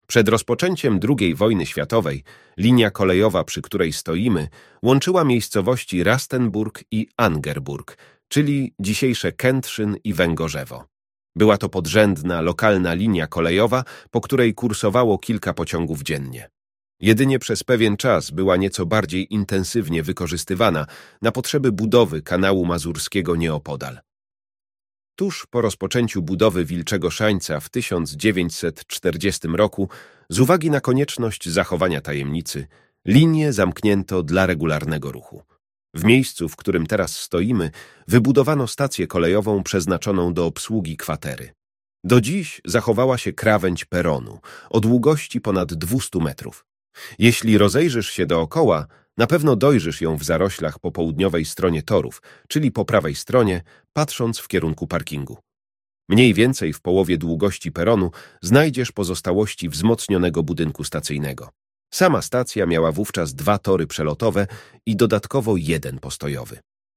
Audioprzewodnik po Strefie 2